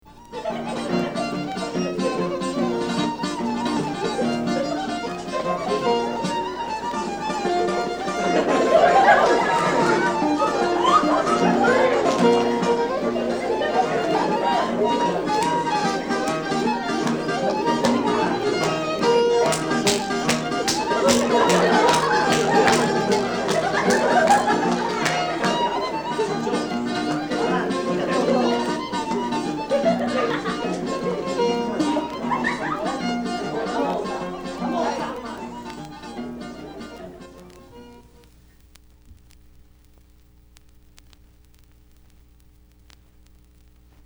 NORD E CENTRO ITALIA - DALLE RICERCHE DI ALAN LOMAX )1954)
20-trescone.mp3